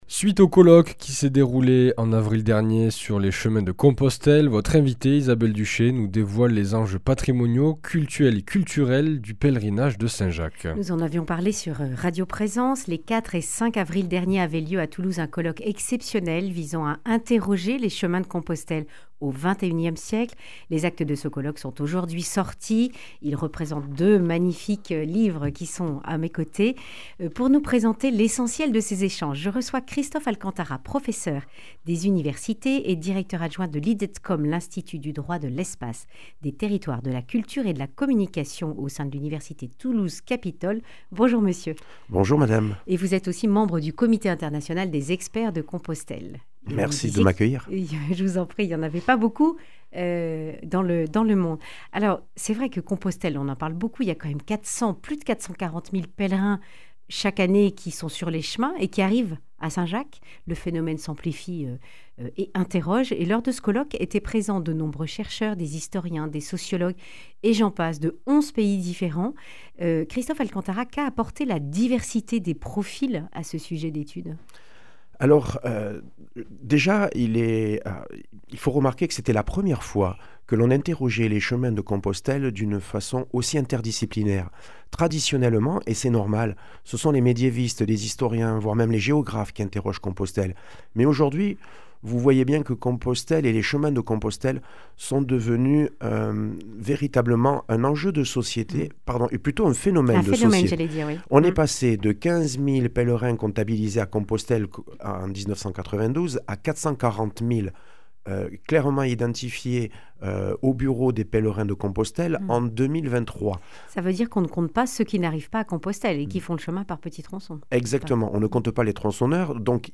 Accueil \ Emissions \ Information \ Régionale \ Le grand entretien \ Que nous disent les chemins de Compostelle au XXIe siècle ?